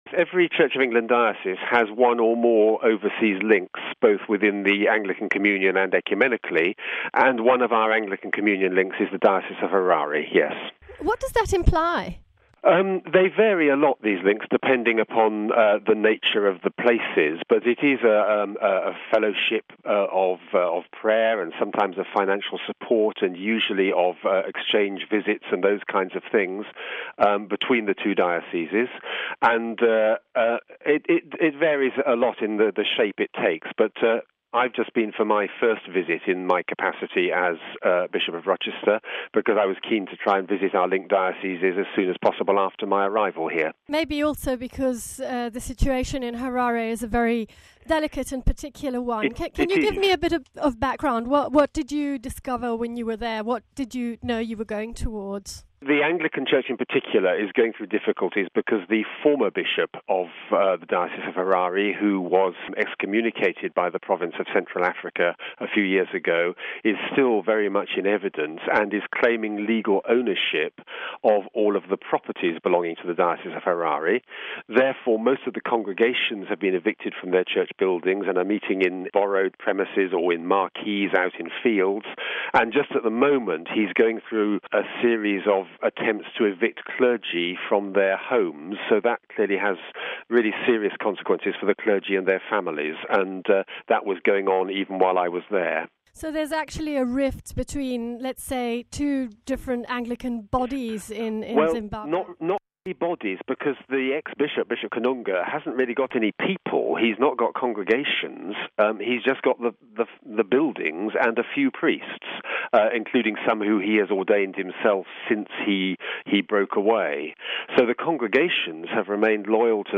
Bishop Langstaff explains the special link that exists between Rochester and Harare, and speaks of the difficulties currently faced by Anglican Priests and their congregations in Zimbabwe... listen to the full interview...